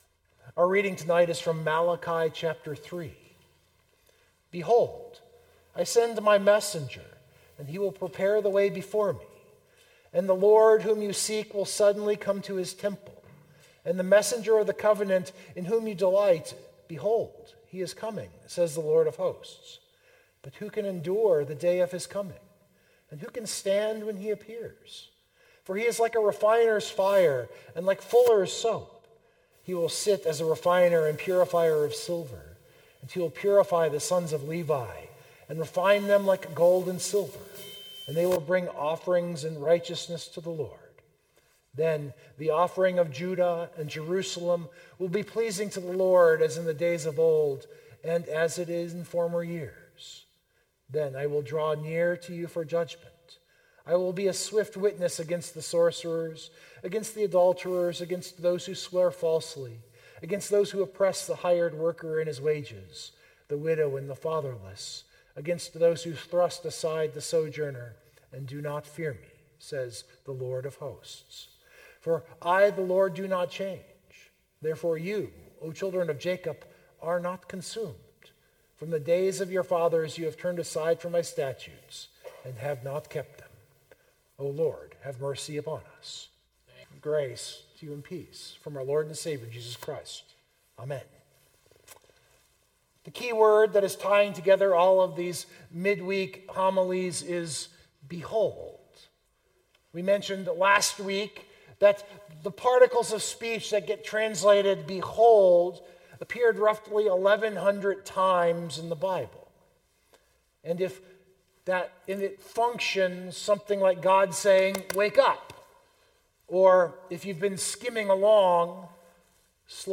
This is the second midweek advent service following the theme of “Behold”.